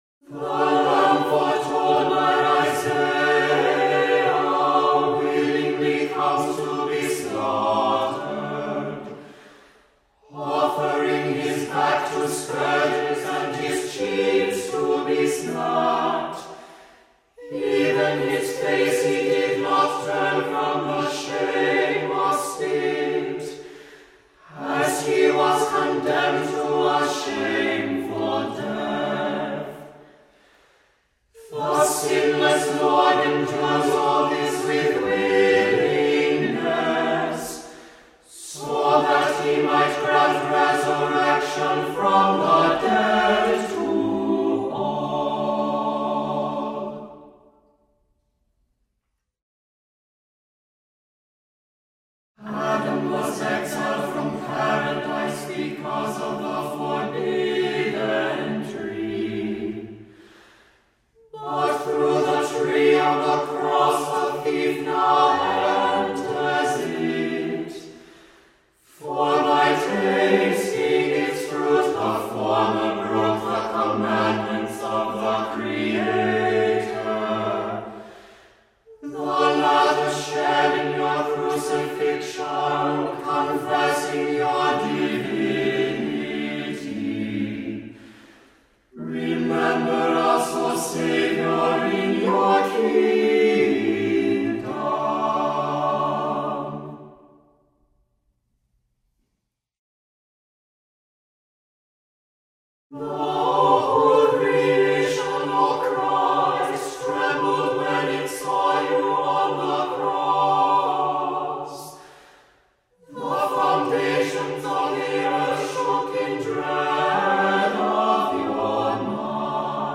Voicing: SATB,a cappella